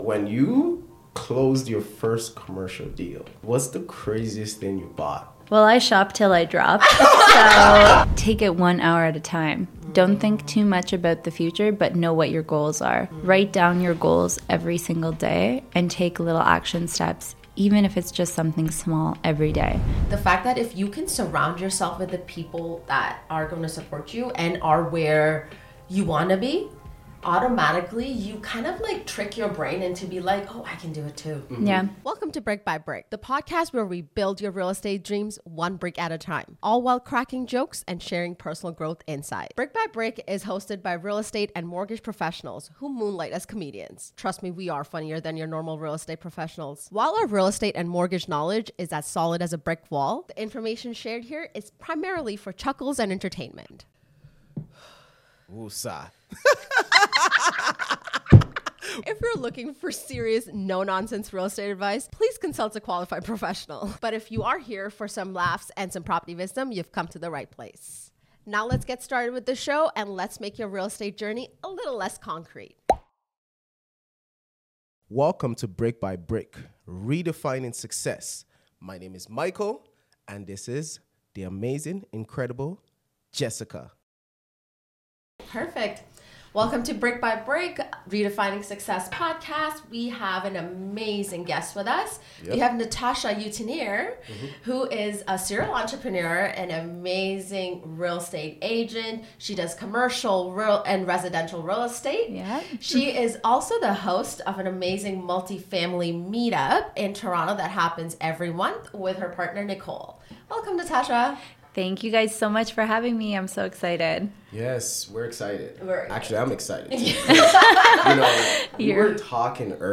Meet our star guest: a real estate agent changing the game!